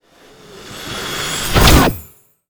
magic_conjure_charge1_05.wav